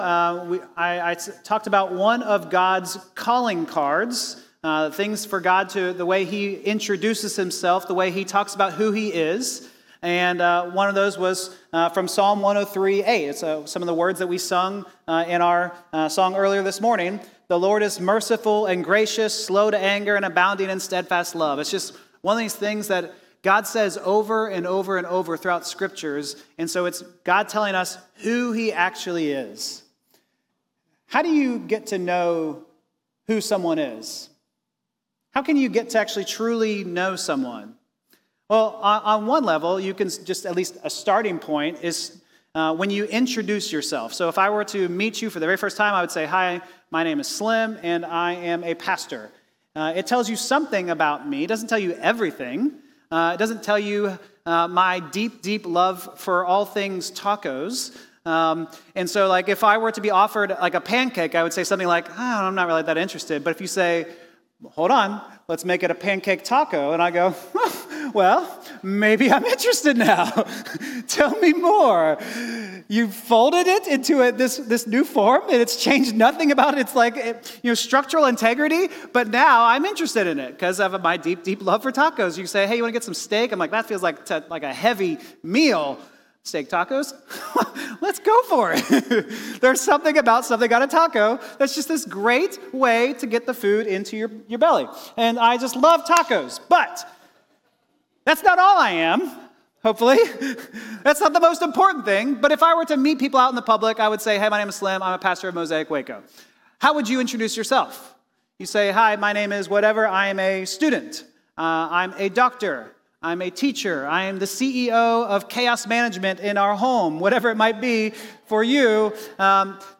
August-24th-sermon-audio.m4a